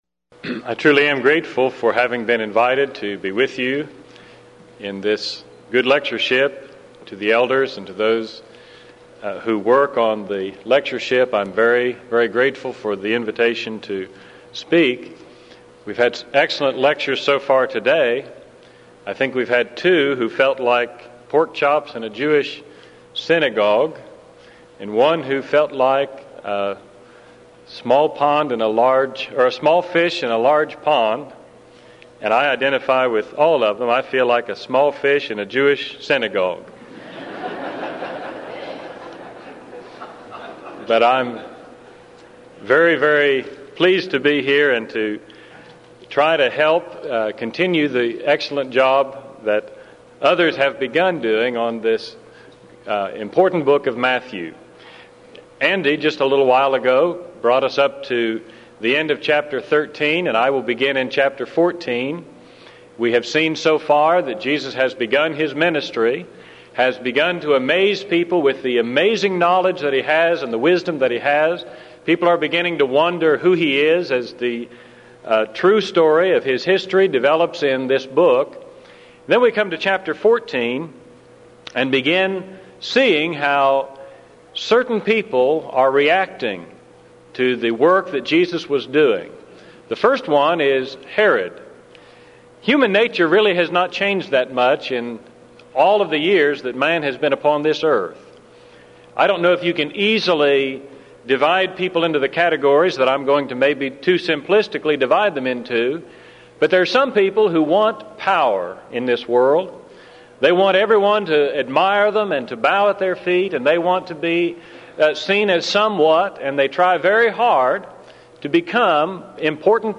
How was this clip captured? Event: 1995 Denton Lectures Theme/Title: Studies In Matthew